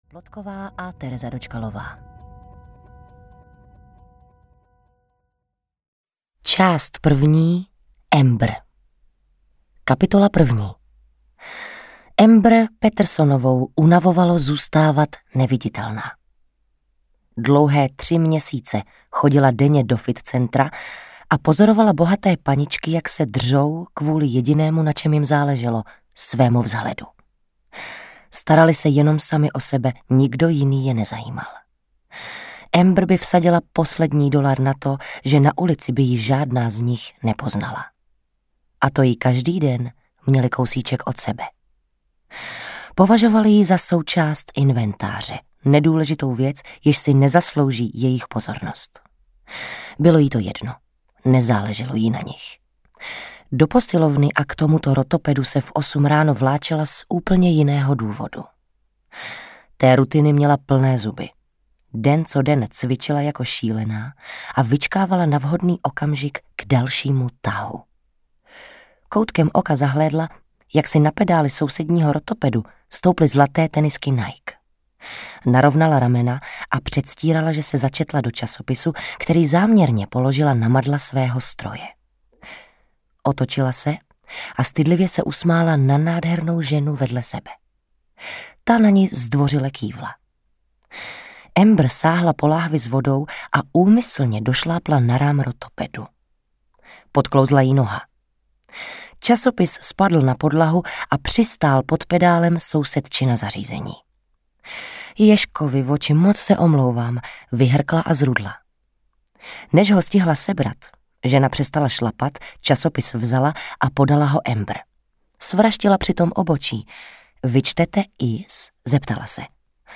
Ukázka z knihy
• InterpretTereza Dočkalová, Jana Plodková